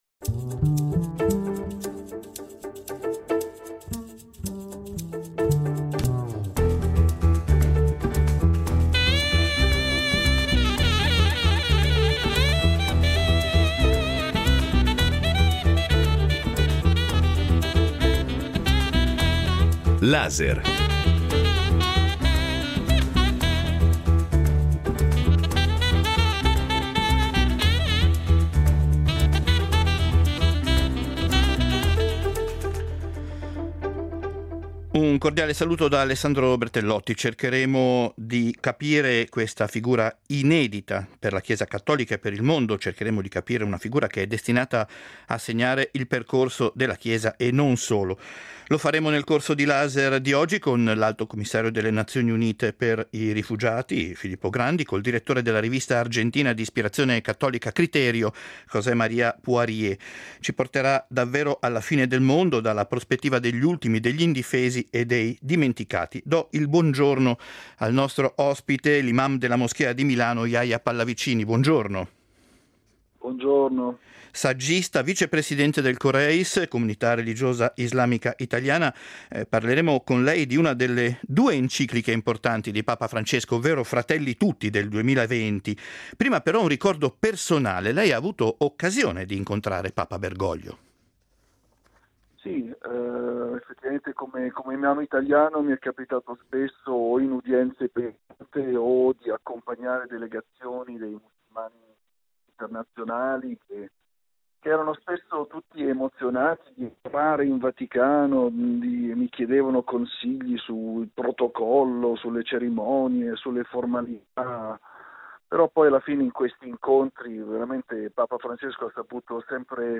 In questo audio documentario raccontano come sono arrivate in Svizzera e cosa si sono lasciate alle spalle, mentre le trame delle loro storie si intrecciano con quella di un viaggio tra Basilea, Ginevra, Delémont, Baden e tutti i luoghi dove hanno trovato rifugio e ora - tra non poche difficoltà e la paura di essere di nuovo costrette alla fuga - stanno cercando di costruirsi una nuova vita, finalmente libere di essere sé stesse.